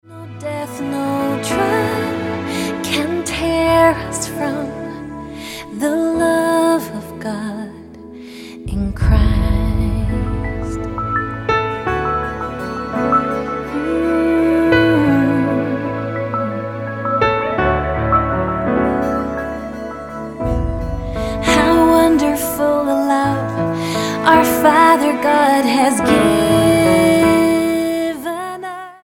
STYLE: MOR / Soft Pop